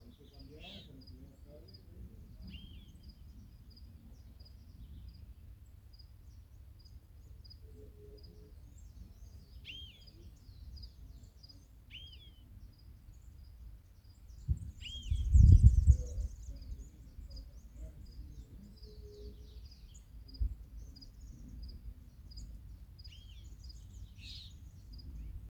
Boyerito (Icterus pyrrhopterus)
Nombre en inglés: Variable Oriole
Condición: Silvestre
Certeza: Observada, Vocalización Grabada